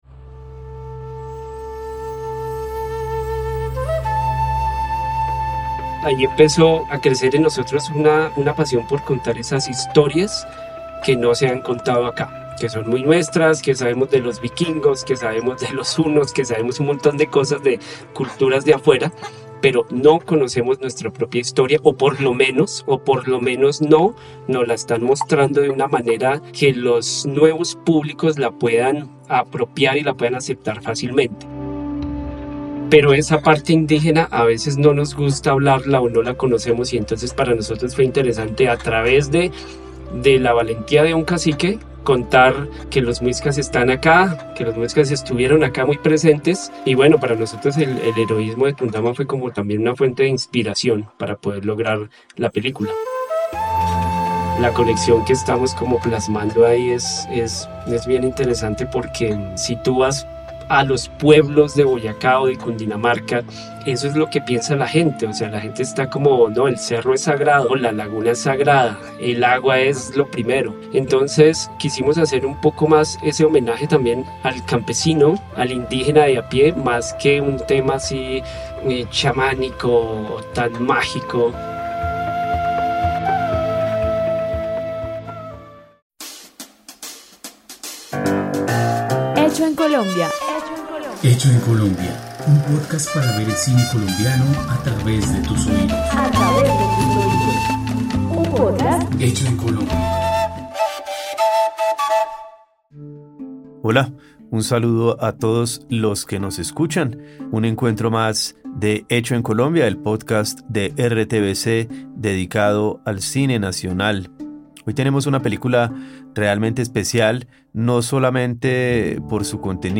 Una conversación para entender la historia de dolor, valentía y resistencia que narra la película animada TUNDAMA.